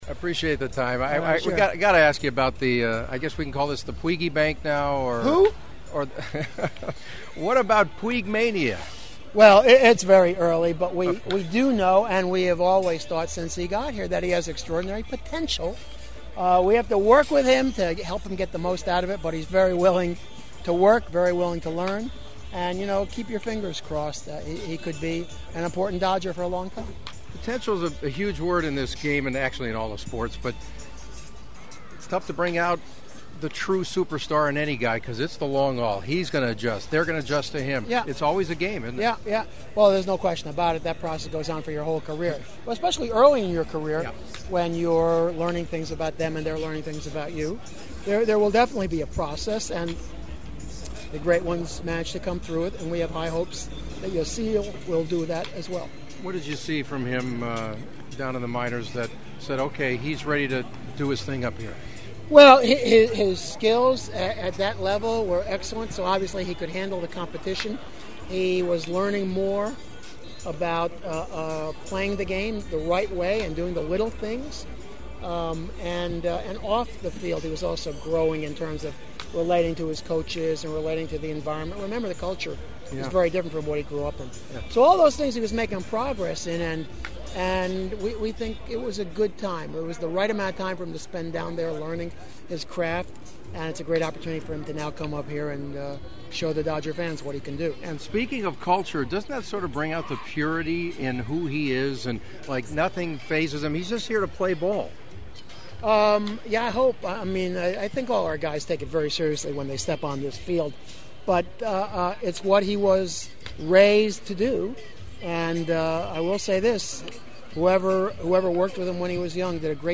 Dodgers President and part-owner Stan Kasten on Puig and the drug issues in baseball: